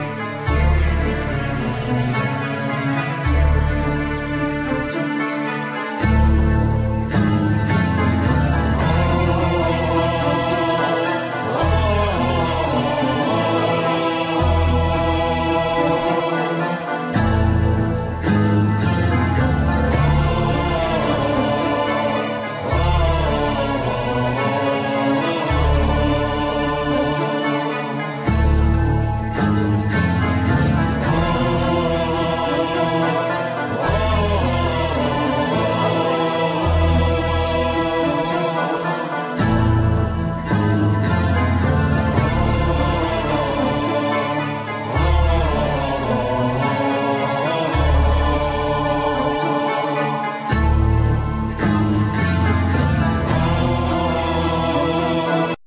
Vocal,Bagpipes,Flute
Guitar,Mandolin,Flute,Back vocals
Drums,Percussions,Keyboards,Back vocal